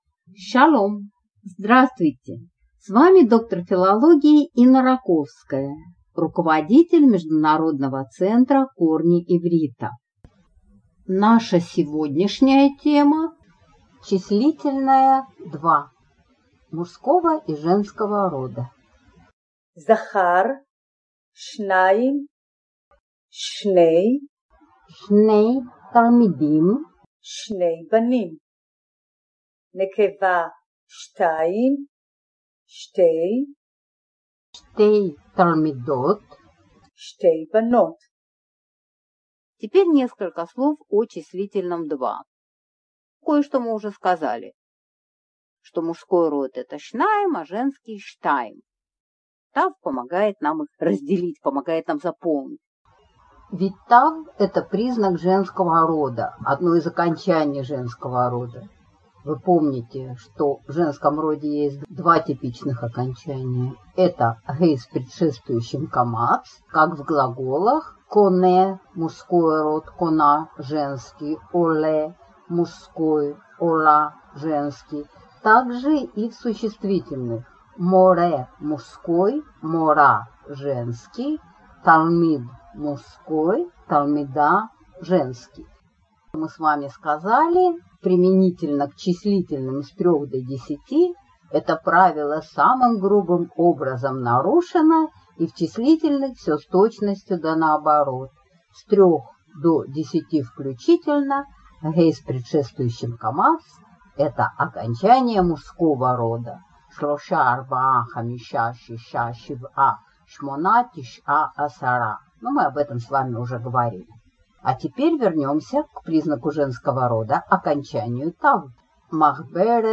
аудио объяснения